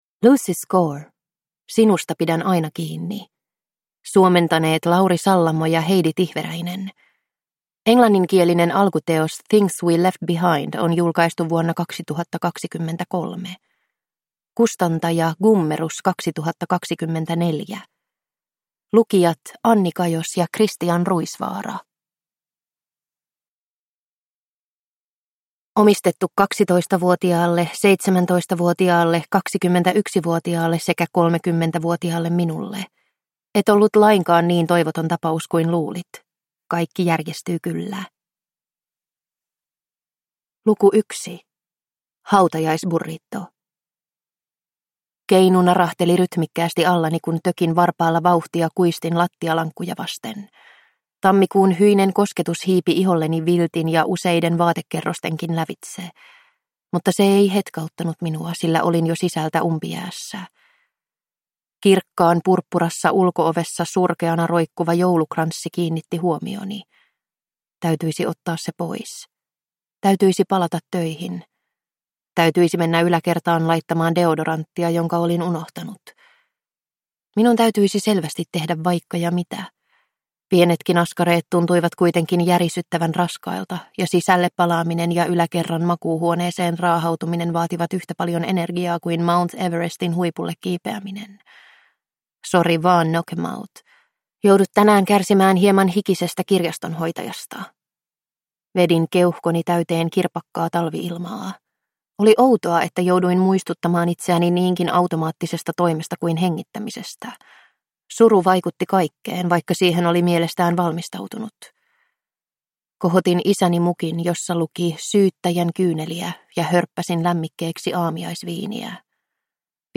Sinusta pidän aina kiinni (ljudbok) av Lucy Score